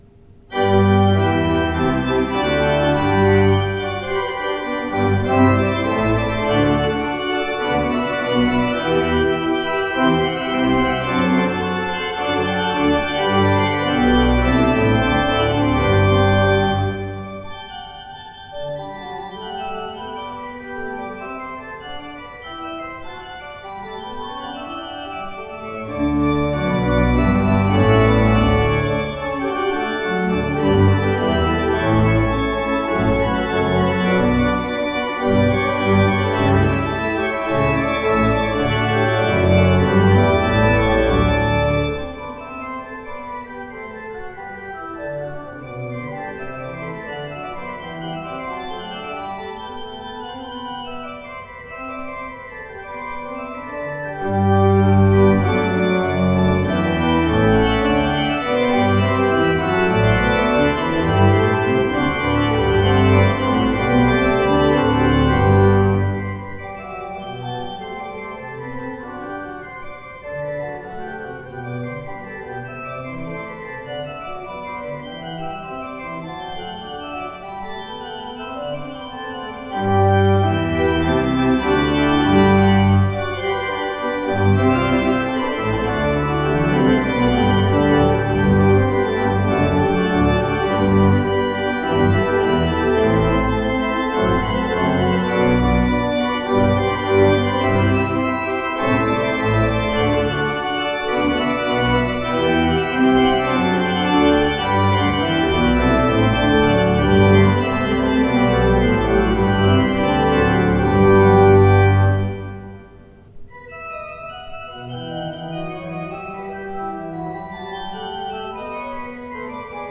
Postludes played at St George's East Ivanhoe 2016
The performances are as recorded on the Thursday evening prior the service in question and are made using a Zoom H4 digital recorder.